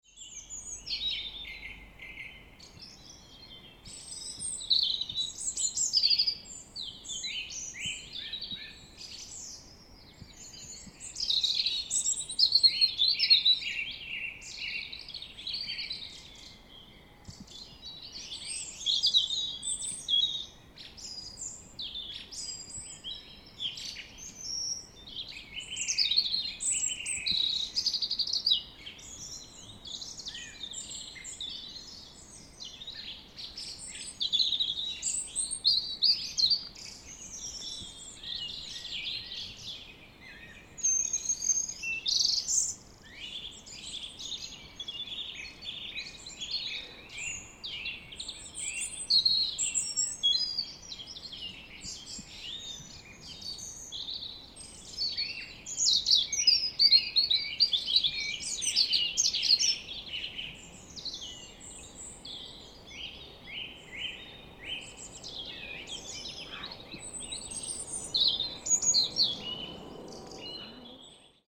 Vesak Dawn Chorus
Coming down Orleans Road towards the riverside, a Song Thrush dawn song (in amongst the Robins).
And when I hear those distinctive, repeating phrases, that sharp brightness that just reaches out beyond other birdsong, I orientate around and towards them, aural magnetism.
song-thrush-twickenham_1-2.mp3